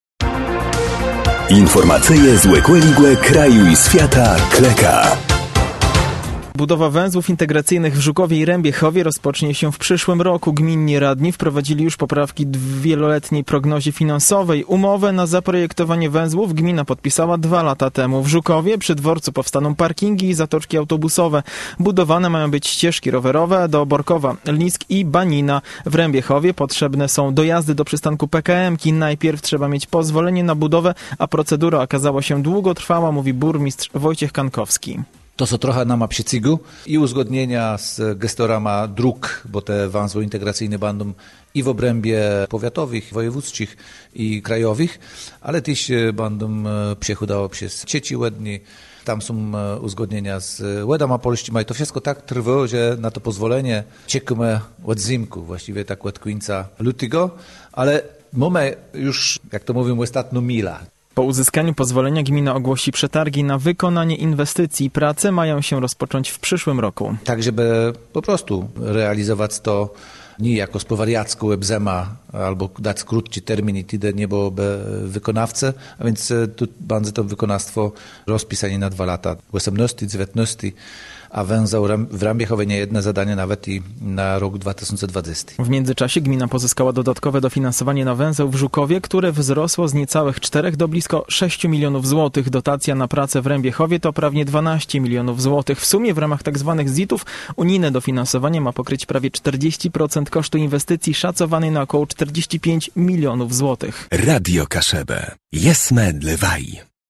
– Najpierw trzeba mieć pozwolenie na budowę, a procedura okazała się długotrwała – mówi burmistrz Wojciech Kankowski.